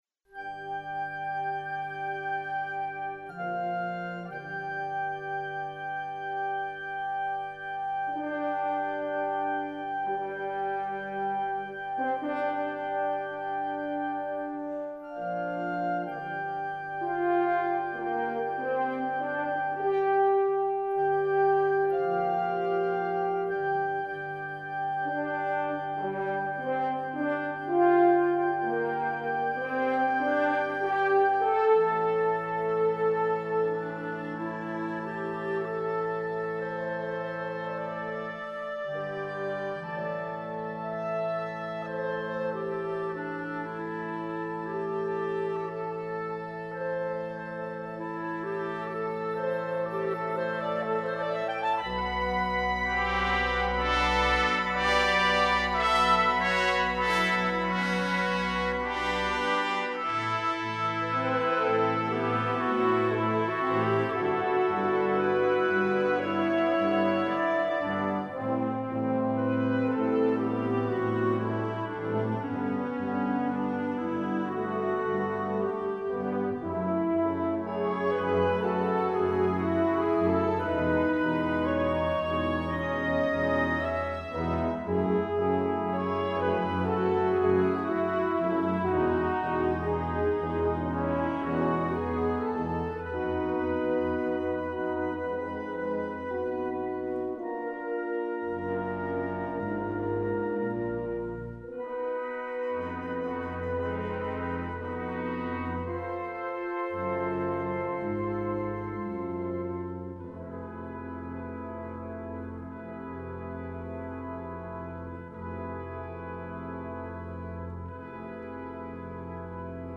concert bands